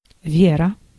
Ääntäminen
IPA: /ˈvʲerə/